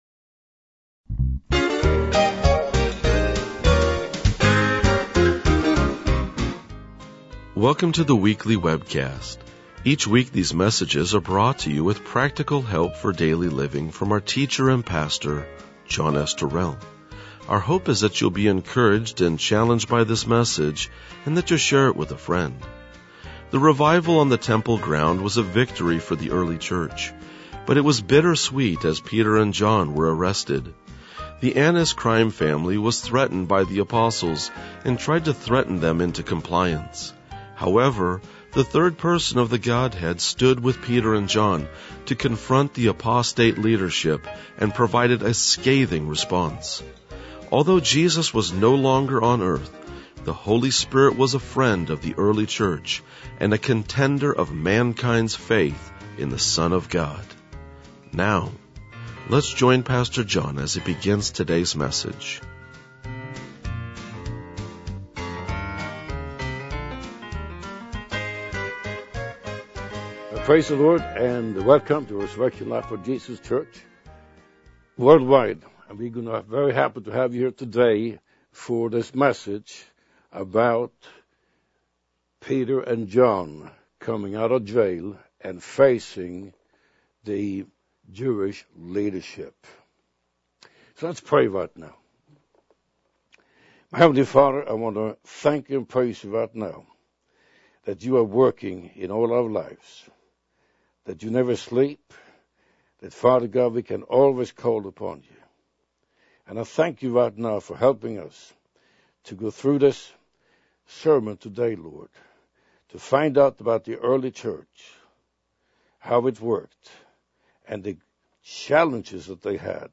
RLJ-2001-Sermon.mp3